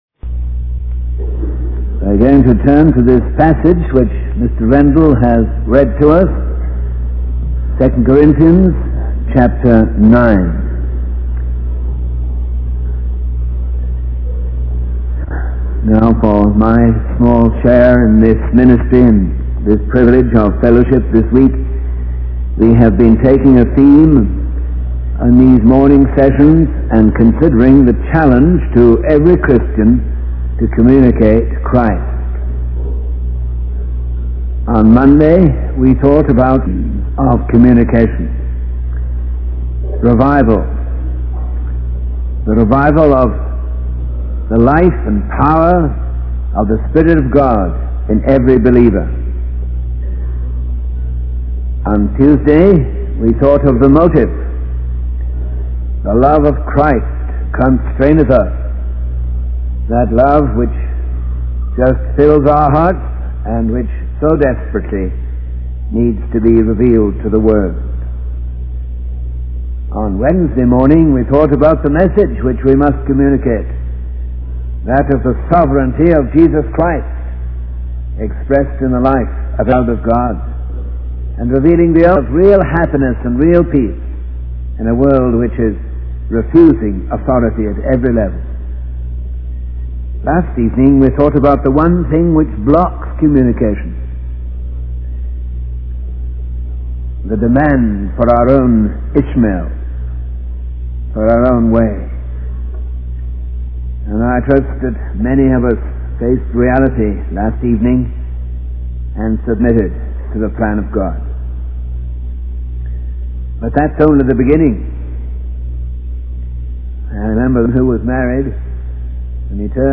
The sermon transcript is focused on the theme of communicating Christ and the challenges faced by Christians in doing so. The speaker emphasizes the need for revival and the love of Christ as the motive for sharing the message.